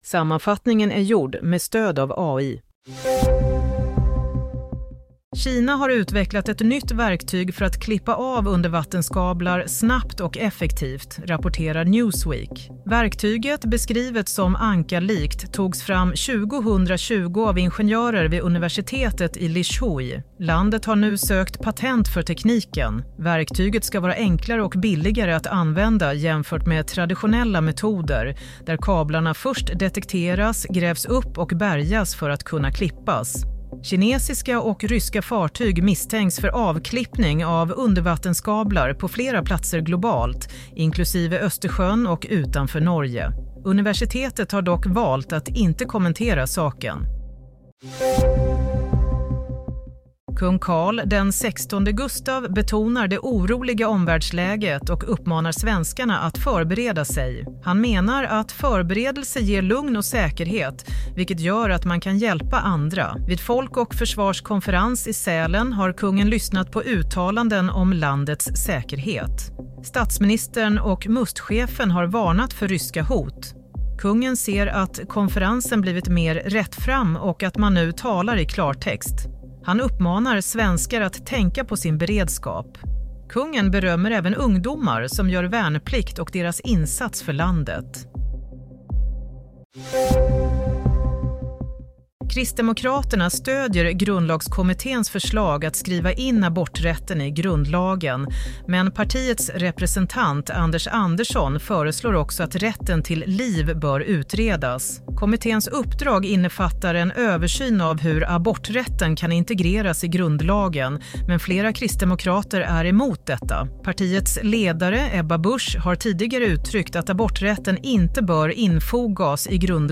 Play - Nyhetssammanfattning – 13 januari 16:00